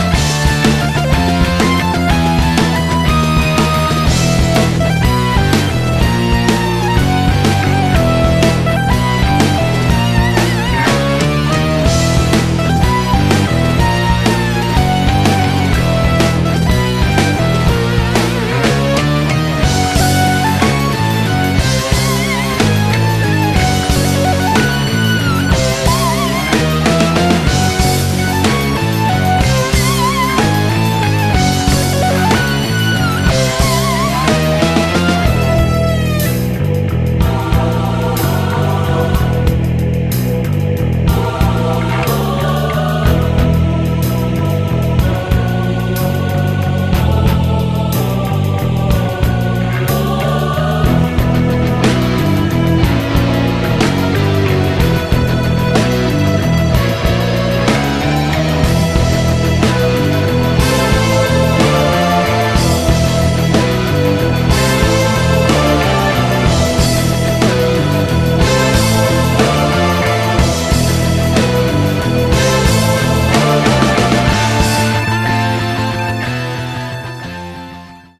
Nouveau nom de la scène progressive française